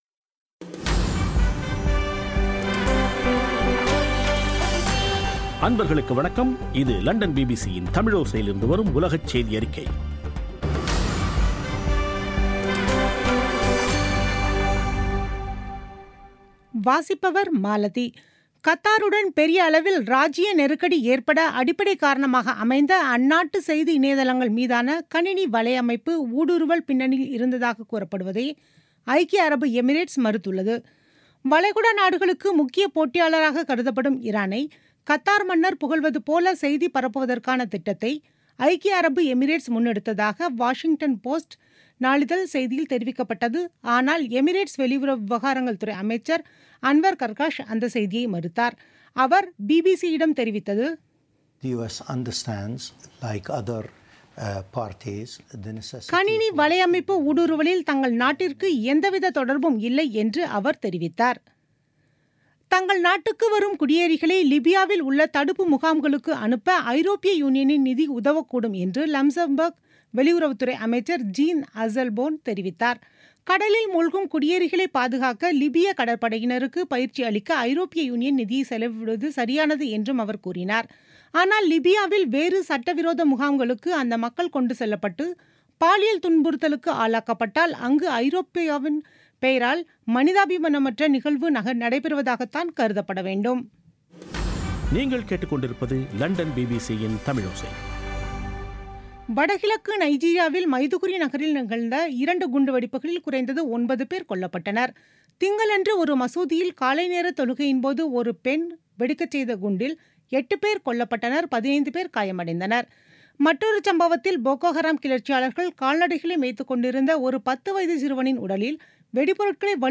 பிபிசி தமிழோசை செய்தியறிக்கை (17/07/2017